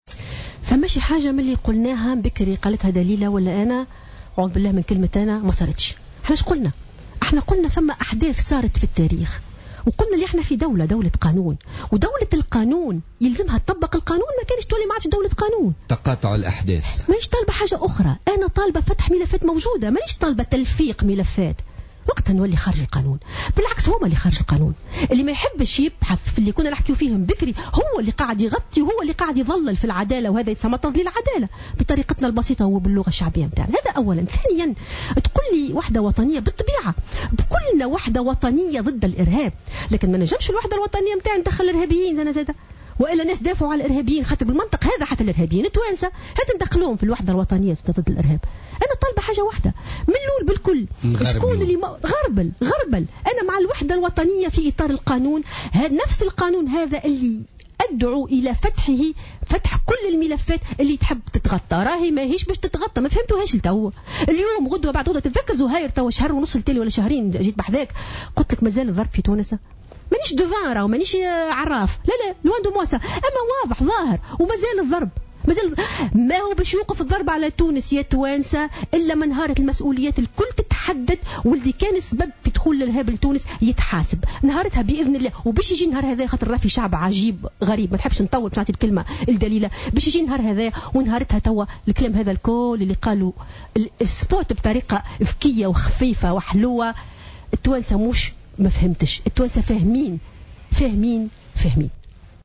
دعت المفكرة والجامعية ألفة يوسف ضيفة برنامج "بوليتيكا" اليوم الثلاثاء إلى فتح الملفات ومحاسبة المتورّطين في استفحال الإرهاب في تونس.